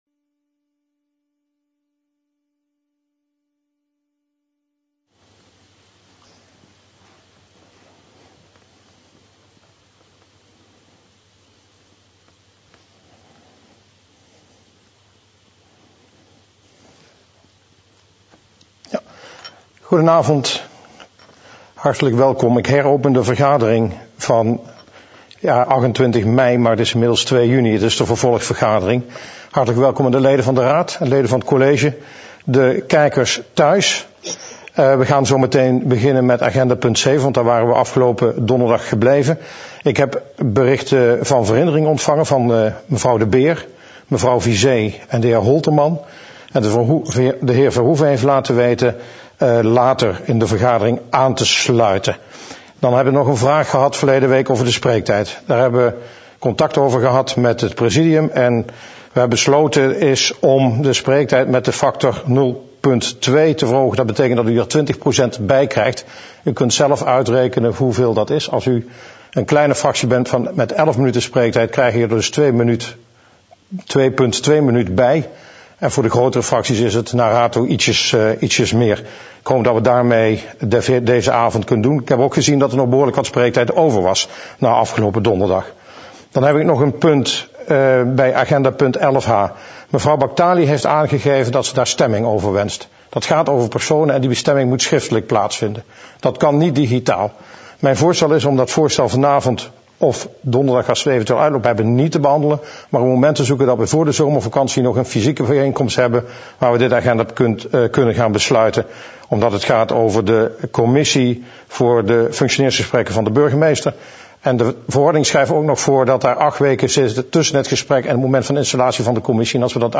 Agenda Nieuwegein - Raadsvergadering dinsdag 2 juni 2020 20:00 - 23:00 - iBabs Publieksportaal
Online, te volgen via livestreaming